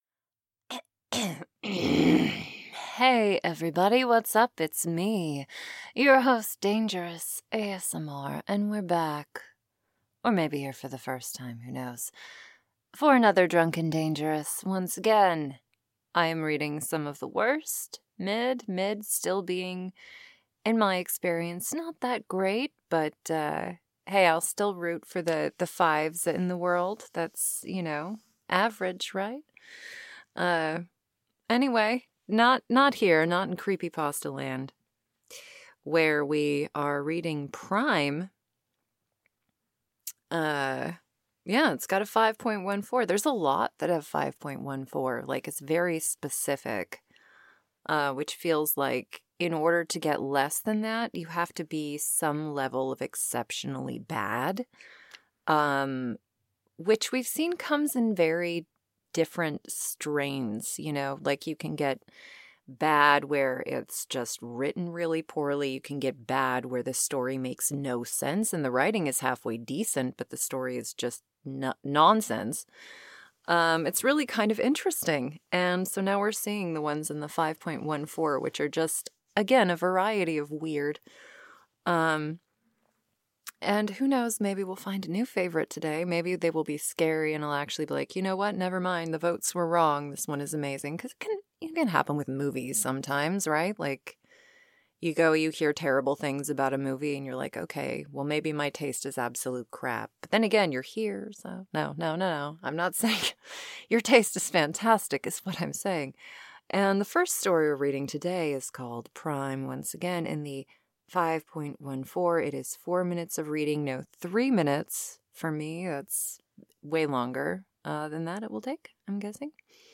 Join me once again as I rant, rave, and read more of the internet's worst-rated scary fiction.